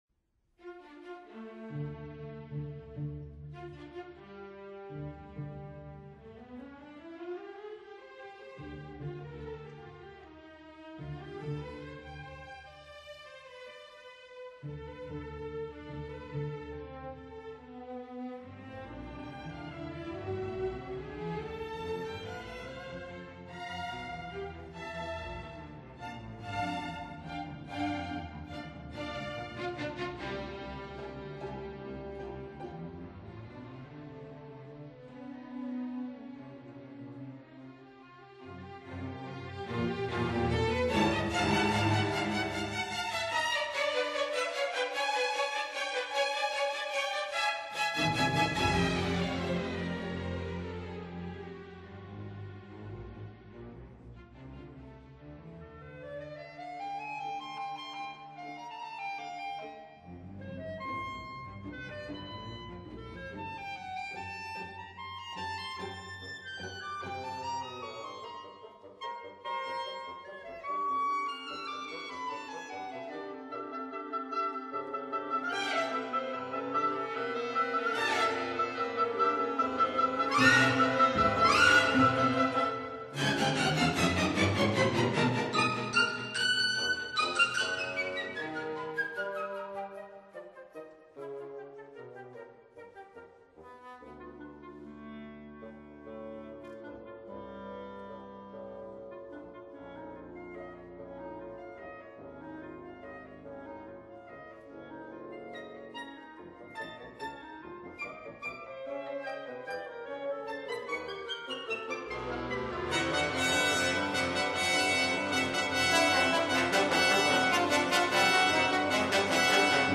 分辑：CD1-CD11 交响曲全集